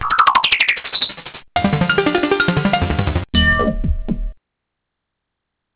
Fleuron de la synthèse en 1980 : le Roland Jupiter 8, un polyphonique 8 voix à deux oscillateurs par voix, doté de 40 mémoires... (cliquez dessus pour entendre un exemple sonore)